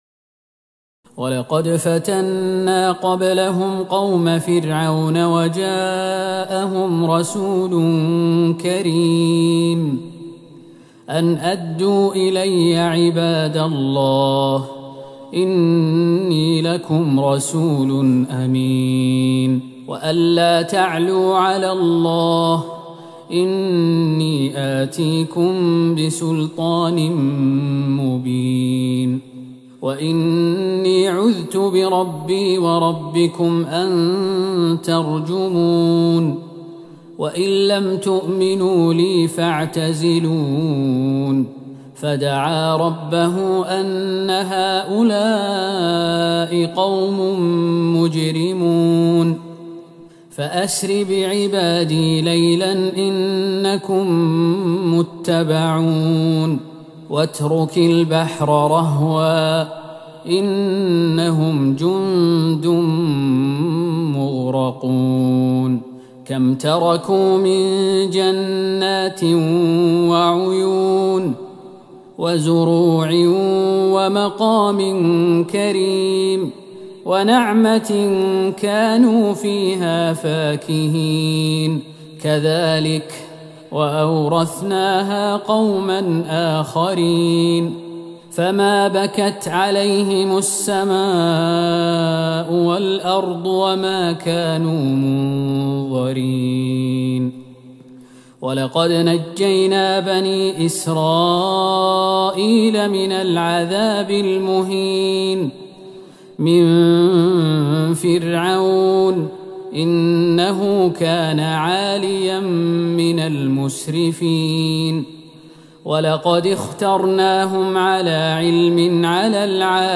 تراويح ٢٧ رمضان ١٤٤١هـ من سورة الدخان ١٧- النهاية والجاثية والأحقاف كاملتين > تراويح الحرم النبوي عام 1441 🕌 > التراويح - تلاوات الحرمين